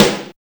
626 SNARE 2.wav